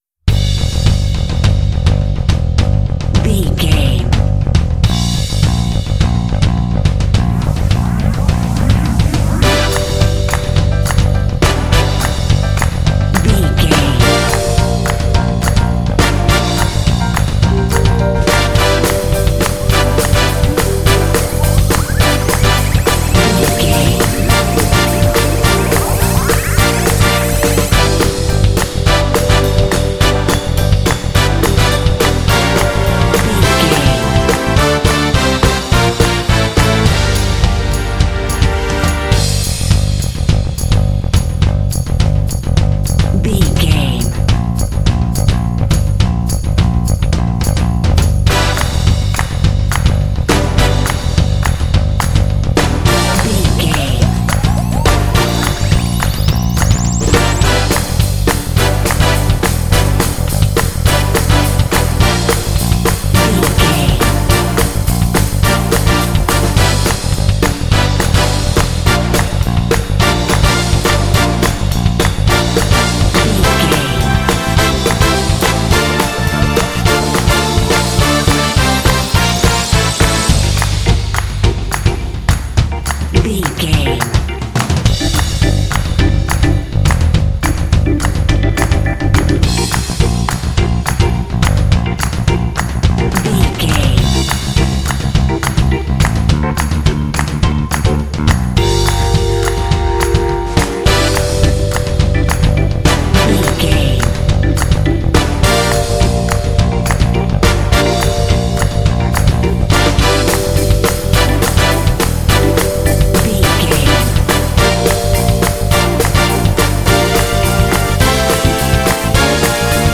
Fast paced
Aeolian/Minor
energetic
dark
groovy
funky
drums
bass guitar
brass
synth-pop
new wave